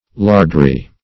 lardry - definition of lardry - synonyms, pronunciation, spelling from Free Dictionary Search Result for " lardry" : The Collaborative International Dictionary of English v.0.48: Lardry \Lard"ry\, n. [See Lardery .]